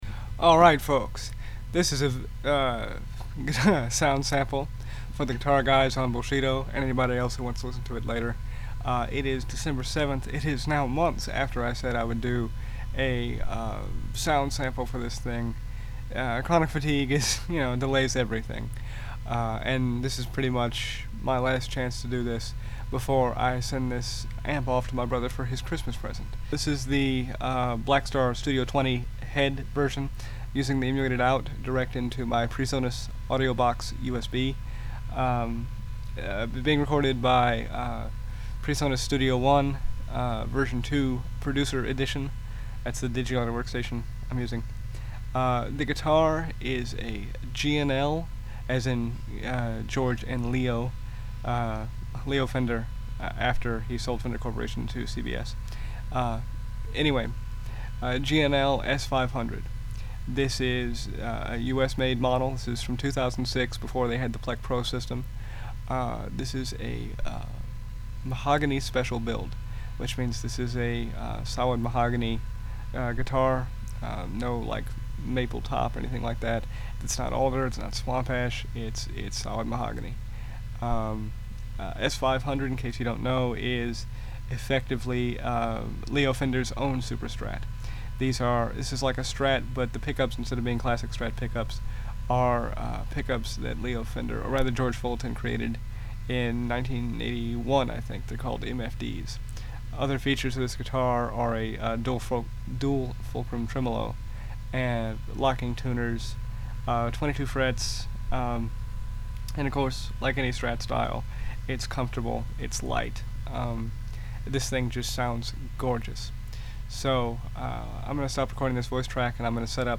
GuitarSample-GAndLS500_BlackstarStudio20H.mp3